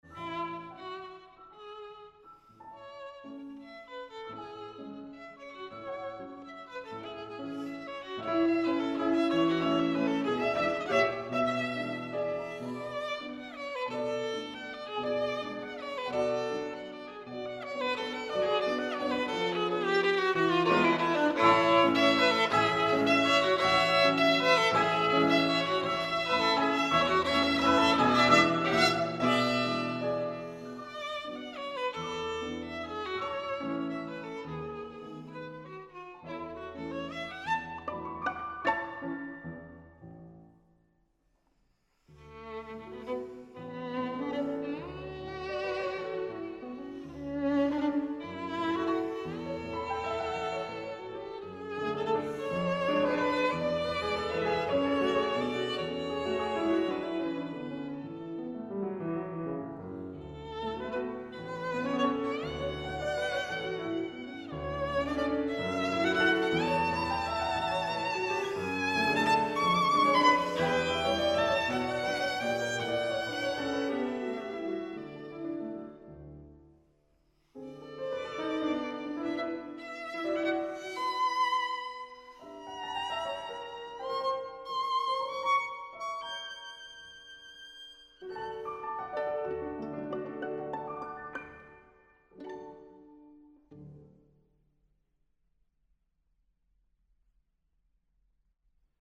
Six pieces for violin and piano P.31